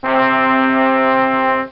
Trombones Sound Effect
trombones.mp3